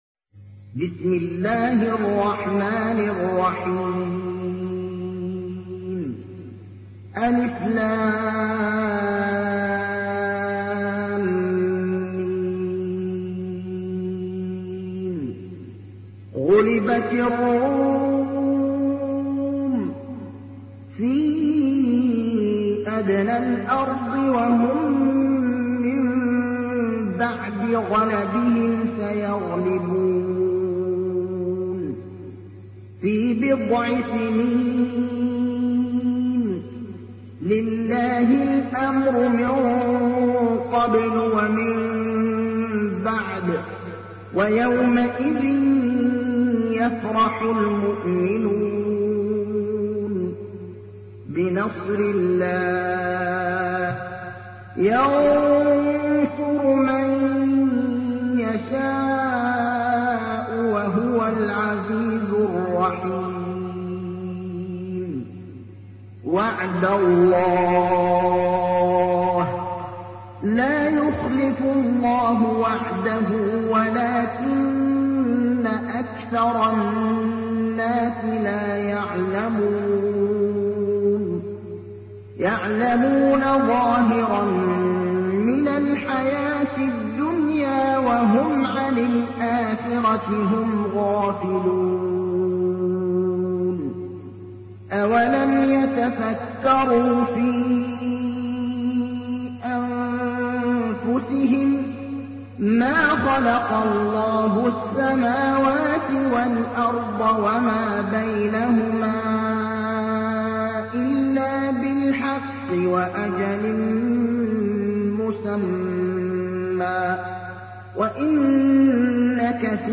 تحميل : 30. سورة الروم / القارئ ابراهيم الأخضر / القرآن الكريم / موقع يا حسين